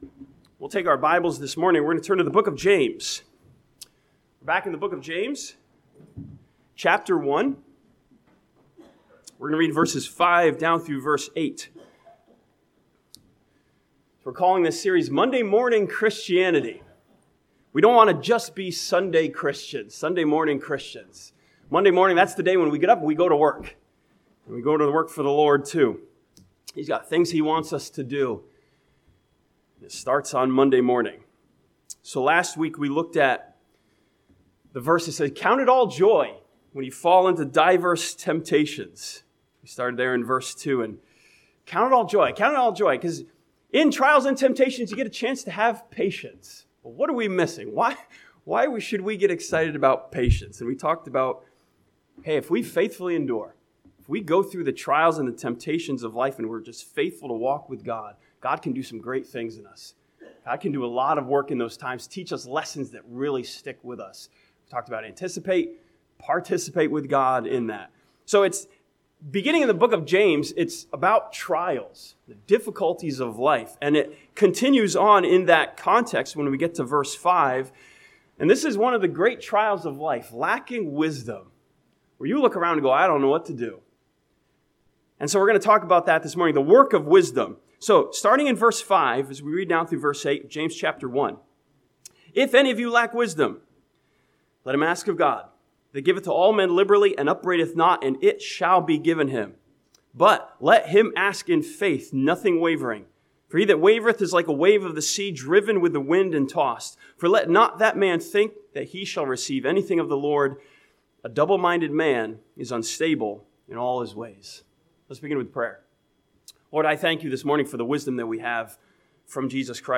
This sermon from James chapter 1 studies the work of wisdom in trusting God to give us the wisdom that we need.